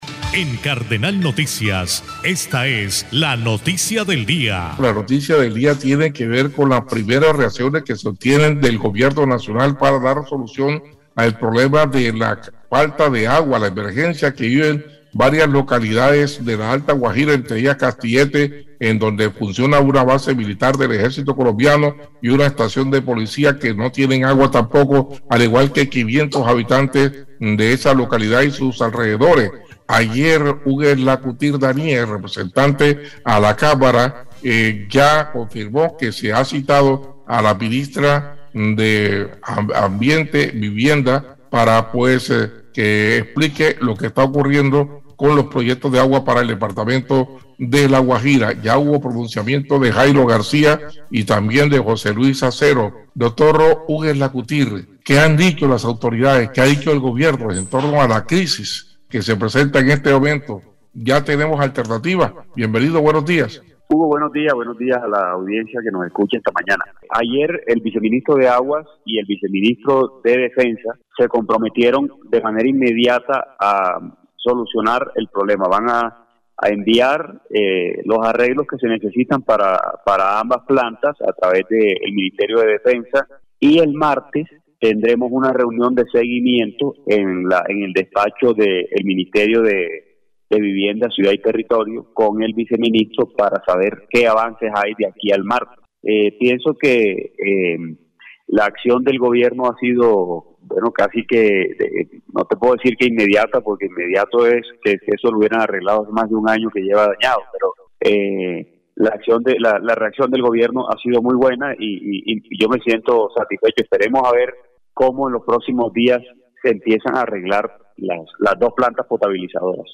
5-DE-MAYO-VOZ-HUGUES-LACOUTURE-.mp3